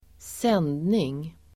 Uttal: [²s'en:dning]